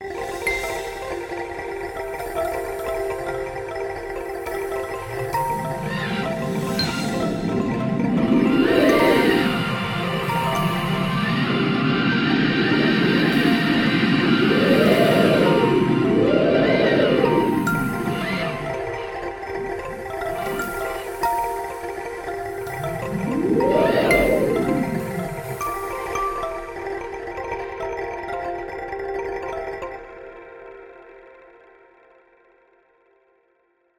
The pitch in a sonification of Chandra and James Webb data changes in relationship to the brightness in different ways. The background galaxies and foreground stars in the visual images Webb detects are mapped to different notes on a synthetic glass marimba. Meanwhile, stars with diffraction spikes are played as crash cymbals. The galaxies of Stephan’s Quintet themselves are heard as smoothly changing frequencies as the scan passes over them. The X-rays from Chandra, which reveal a shock wave that has superheated gas to tens of millions of degrees, are represented by a synthetic string sound.
sonify7_stephan_chandra_webb_spitzer.mp3